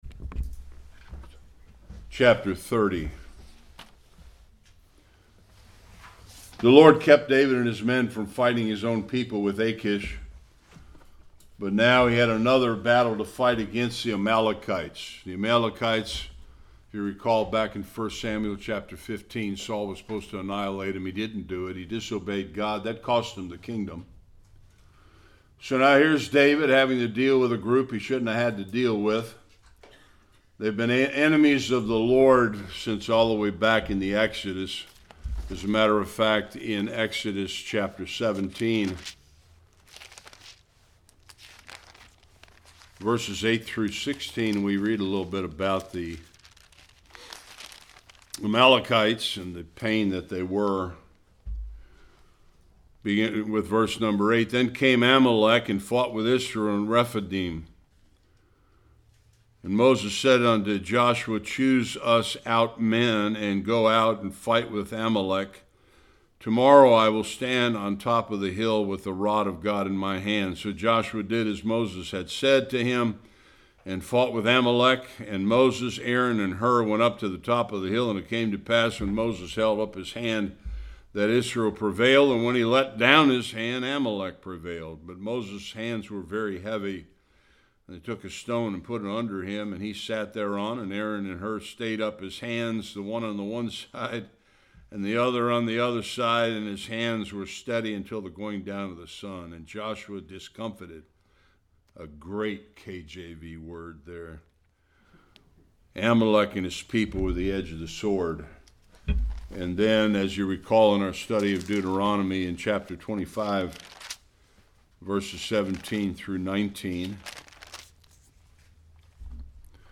1-31 Service Type: Sunday School David seeks the LORD after Ziklag was raided and all the women and children were captured.